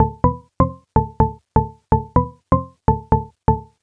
cch_synth_loop_vegas_125_C.wav